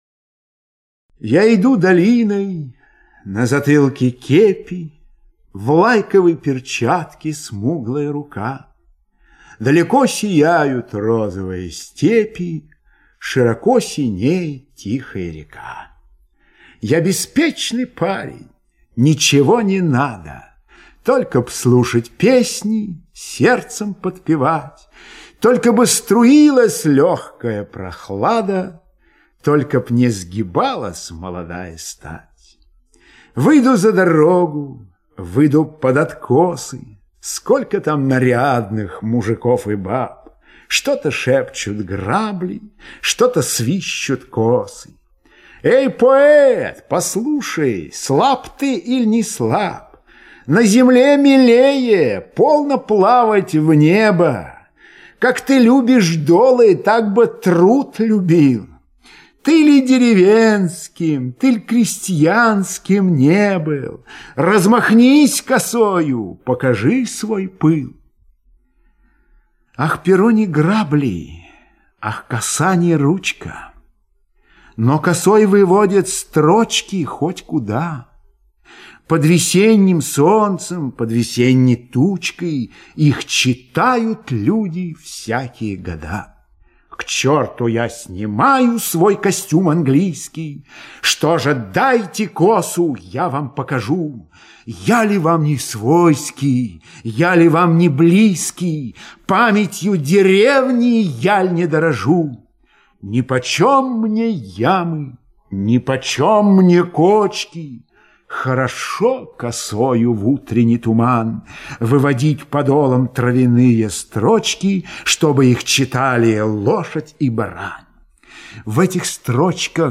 12. «Сергей Есенин – Сергей Есенин – Я иду долиной… – читает Михаил Ульянов» /
Esenin-Sergey-Esenin-Ya-idu-dolinoy.-chitaet-Mihail-Ulyanov-stih-club-ru.mp3